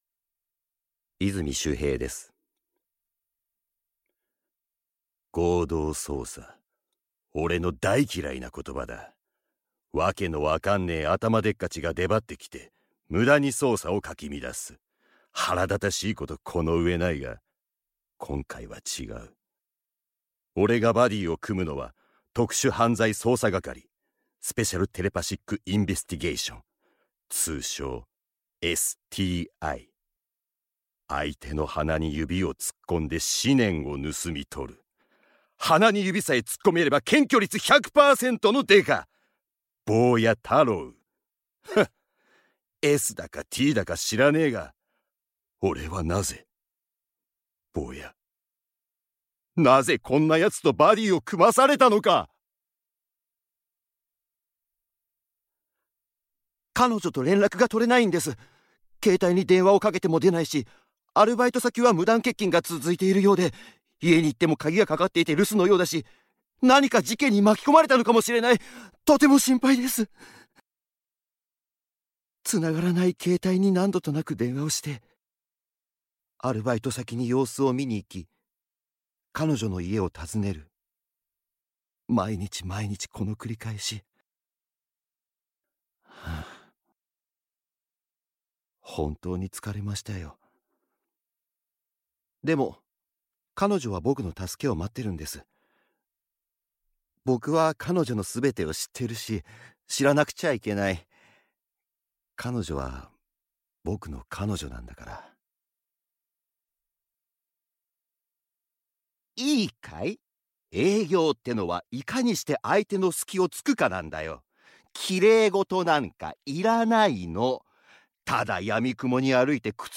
• 俳優
VOICE SAMPLE